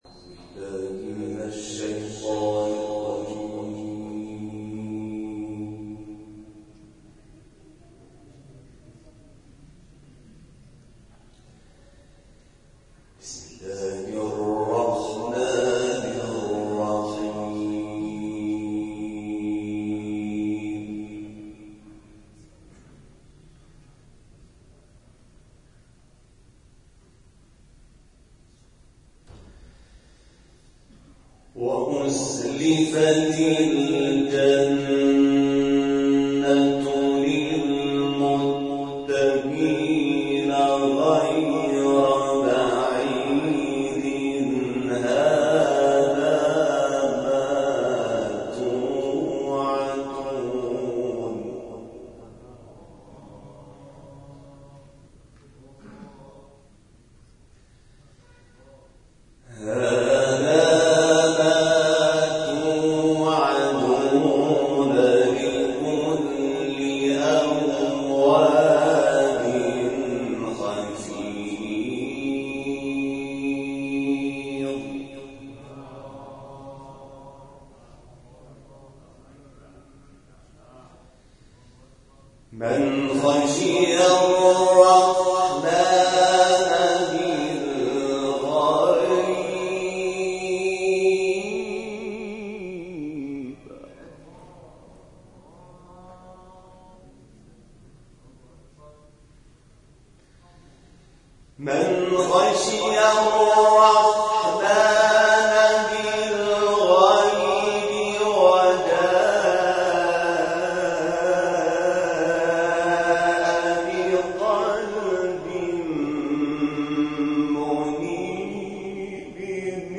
تلاوت حمید شاکرنژاد در کرسی تلاوت نور + صوت و عکس